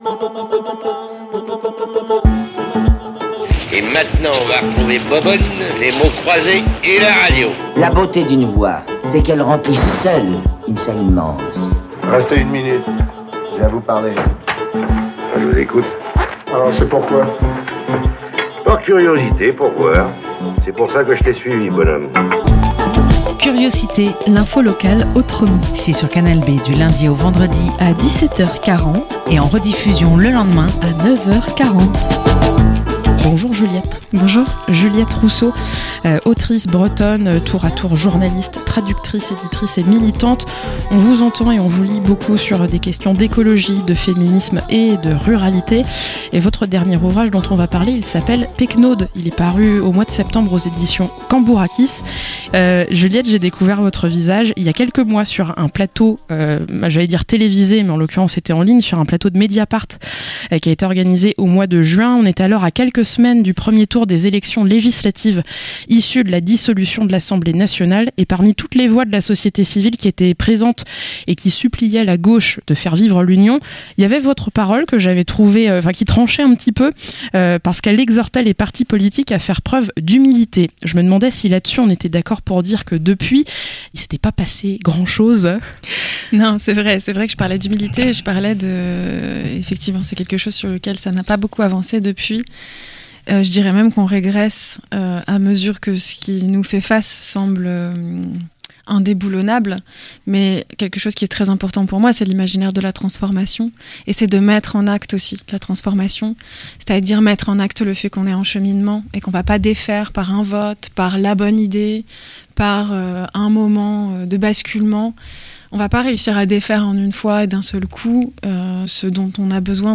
- Interview